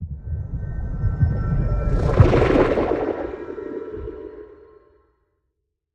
Minecraft Version Minecraft Version 25w18a Latest Release | Latest Snapshot 25w18a / assets / minecraft / sounds / ambient / cave / cave22.ogg Compare With Compare With Latest Release | Latest Snapshot
cave22.ogg